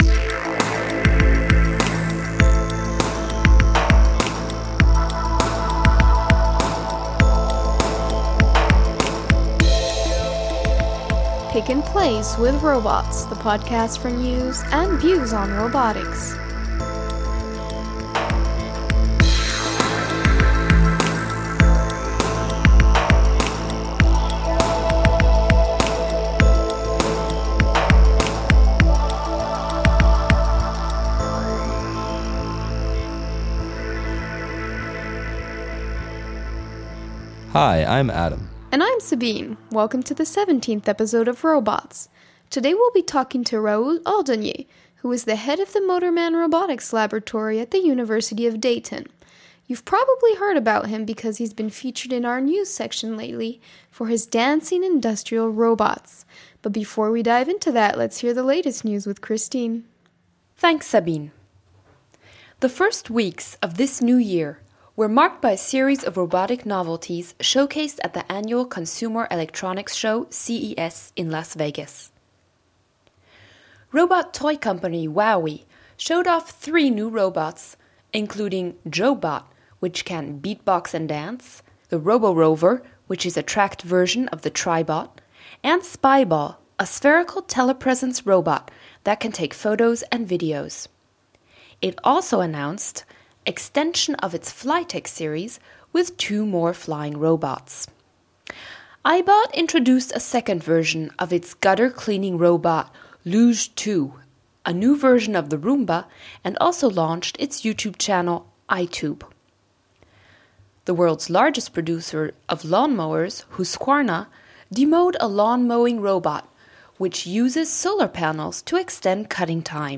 View and post comments on this episode in the forum tags: entertainment , podcast Podcast team The ROBOTS Podcast brings you the latest news and views in robotics through its bi-weekly interviews with leaders in the field.